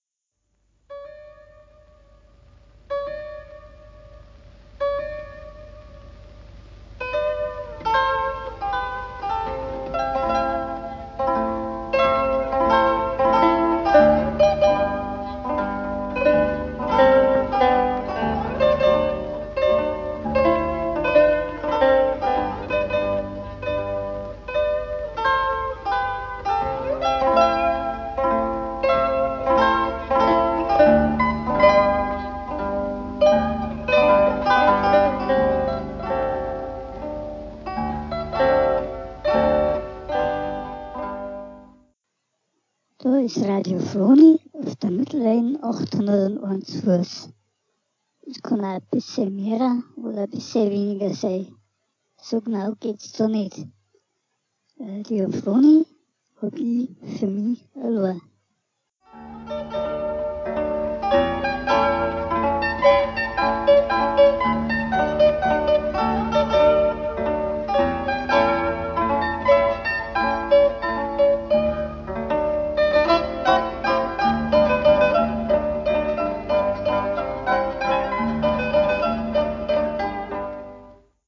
Als Grundlage der Audiodatei wurde von Wikipedia, Zither, das dortige Klangbeispiel verwendet.